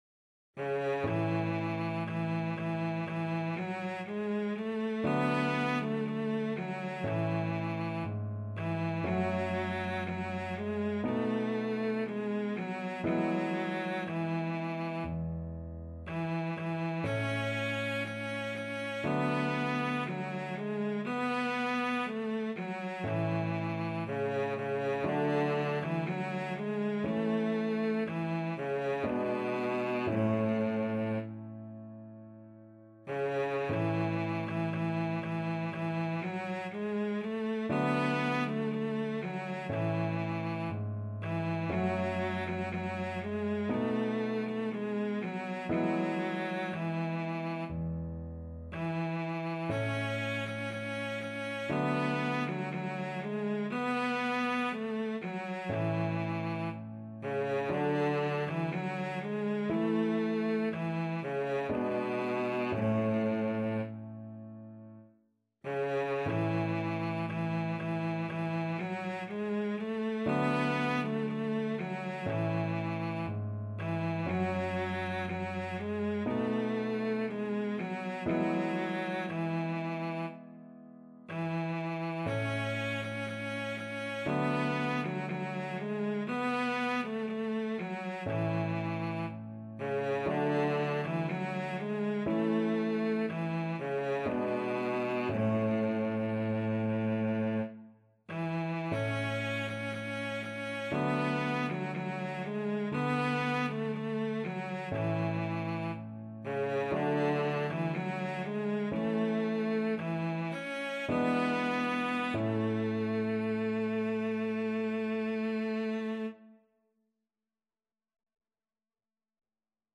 Cello
4/4 (View more 4/4 Music)
A major (Sounding Pitch) (View more A major Music for Cello )
~ = 100 Adagio
Classical (View more Classical Cello Music)